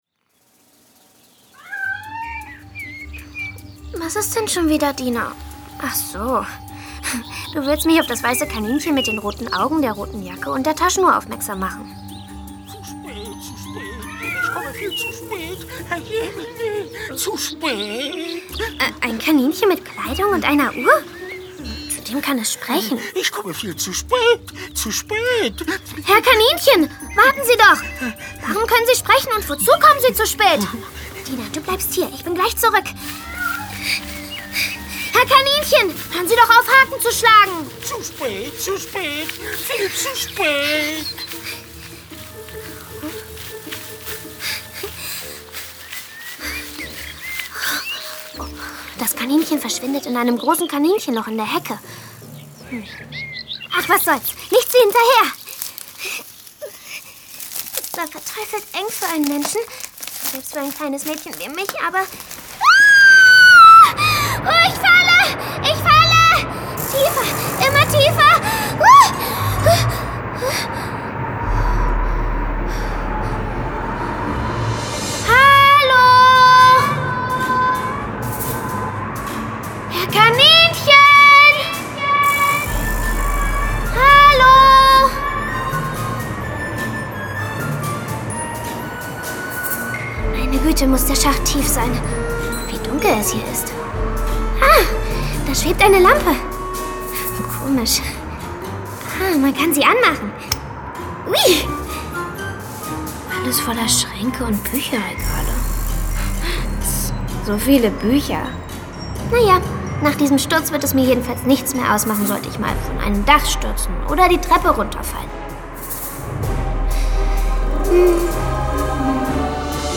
Titania Special 5. Hörspiel.